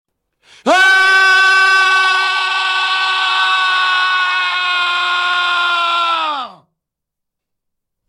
Sound Effects
Loud Male Scream Hd